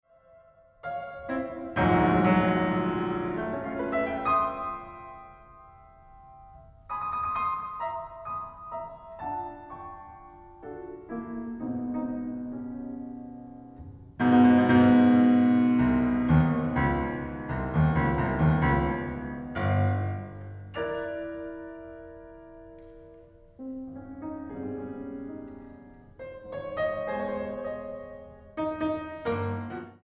pianista